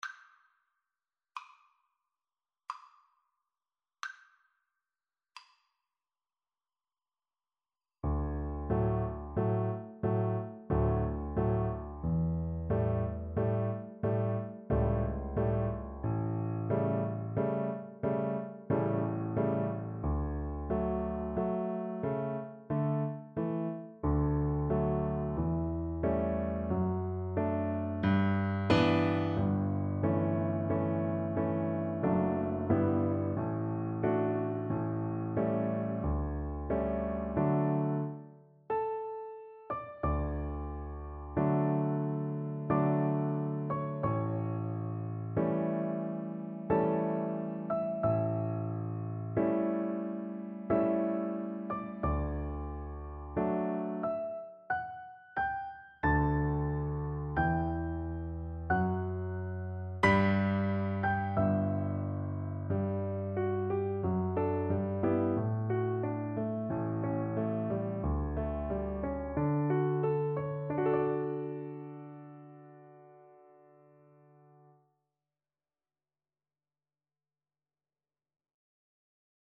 Play (or use space bar on your keyboard) Pause Music Playalong - Piano Accompaniment Playalong Band Accompaniment not yet available transpose reset tempo print settings full screen
3/4 (View more 3/4 Music)
D major (Sounding Pitch) (View more D major Music for Cello )
Adagio =45
Classical (View more Classical Cello Music)